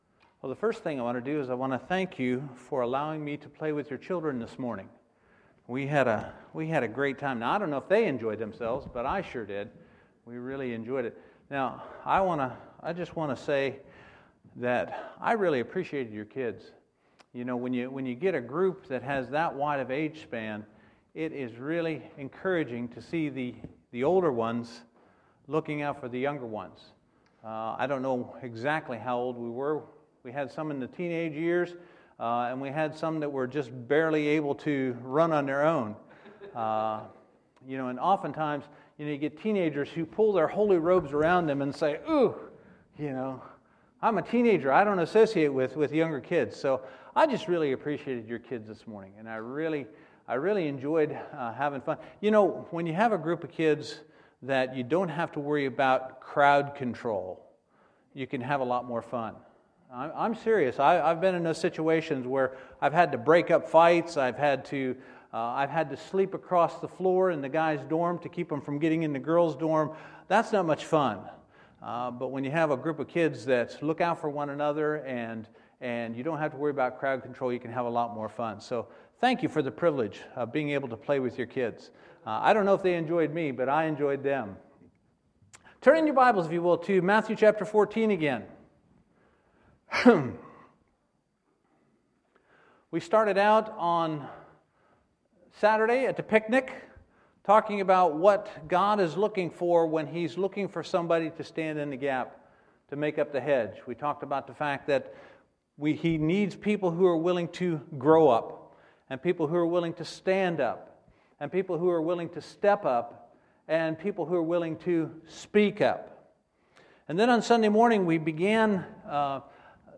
Monday, September 28, 2015 – Missions Conference Monday Evening Service
Sermons